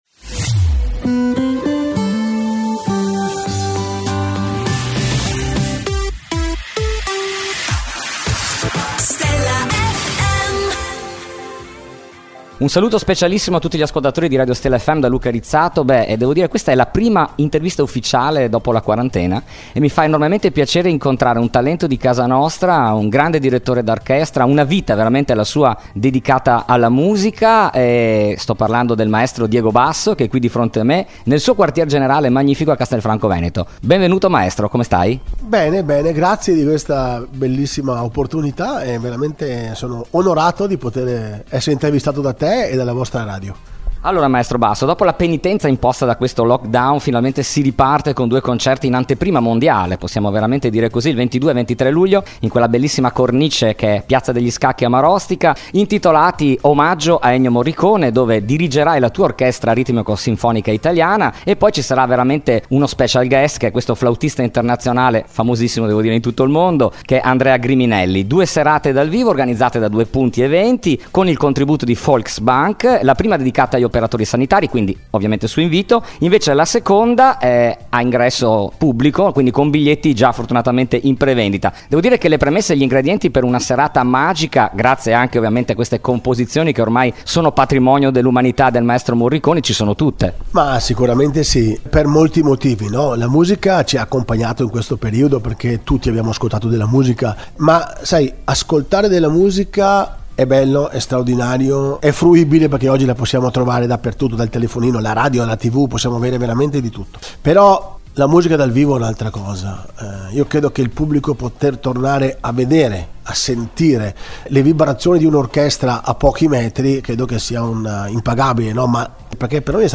Intervista I Diego Basso | Stella FM
Intervista esclusiva dell’inviato per Stella FM a Diego Basso.